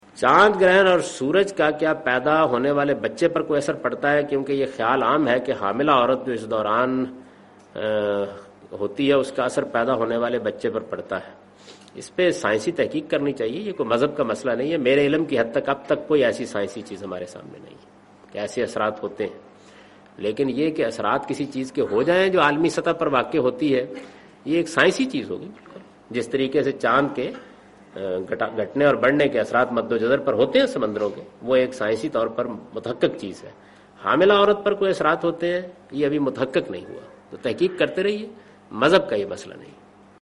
Category: English Subtitled / Questions_Answers /
Javed Ahmad Ghamidi responds to the question 'Does lunar or solar eclipse have any effects on unborn babies'?